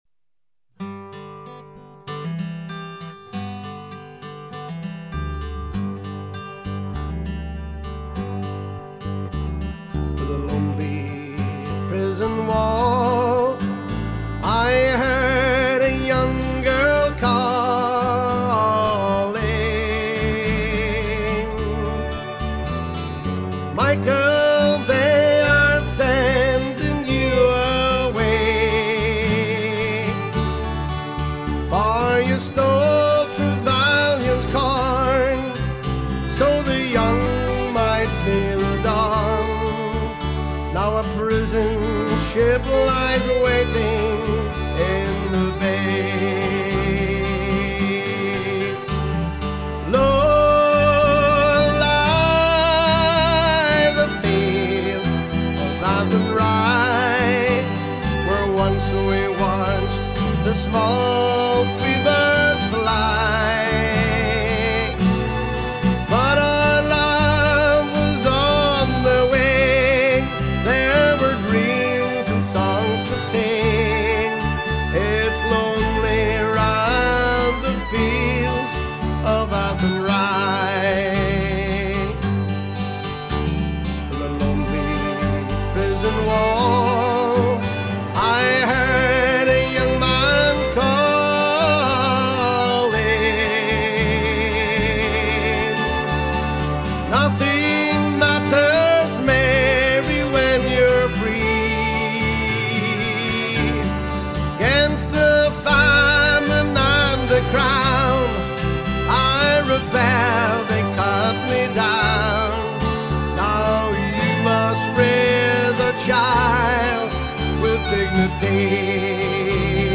Fiddle
Uilleann pipes
Vocals
mp3:  Song